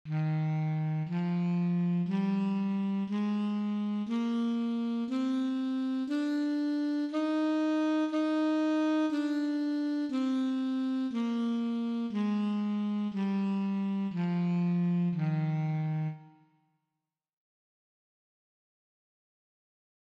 La sua espressività è convincente e duttile come quella del violino, la sua agilità uguale a quella del flauto, le sue possibilità di sfumature ancora superiori a quelle del clarinetto.
Saxofono Contralto
Saxcontralto.mp3